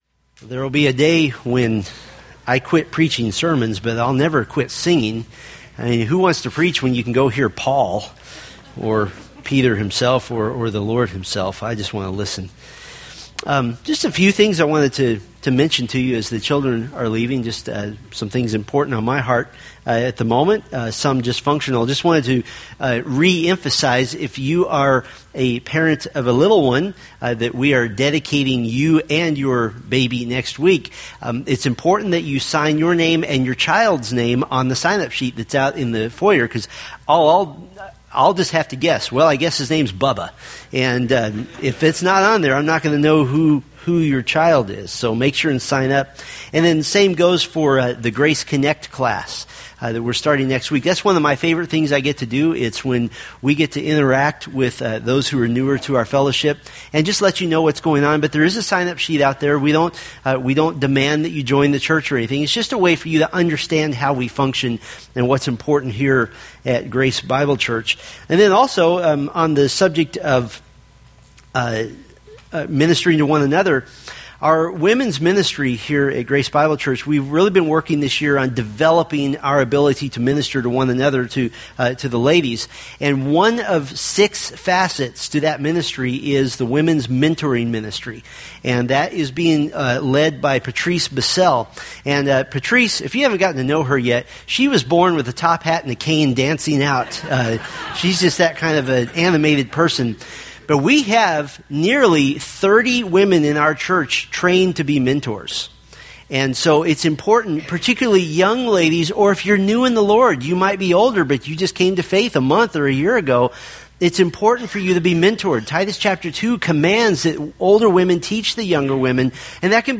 Colossians Sermon Series